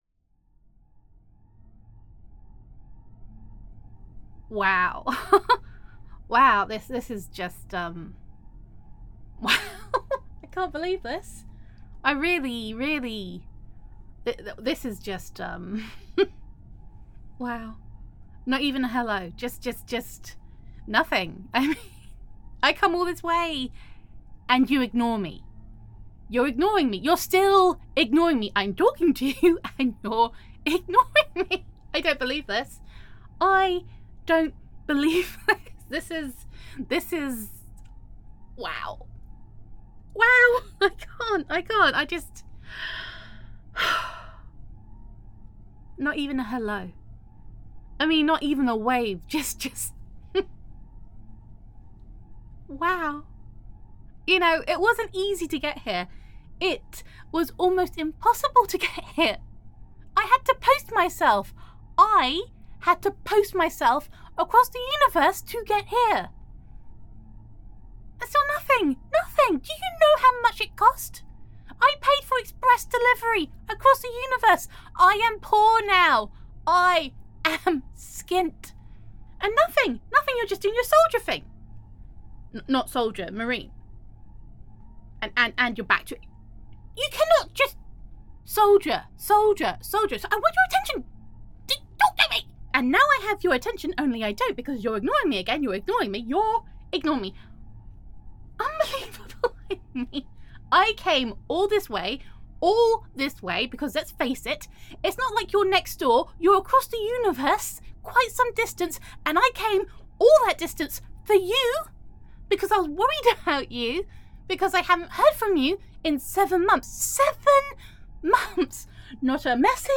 [F4A] Care Package